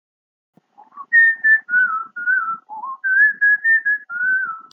Er is een nummer bij zumba die ik altijd zo lekker vrolijk vind, maar ik heb echt geen flauw idee wat ze zingen en welk lied het is.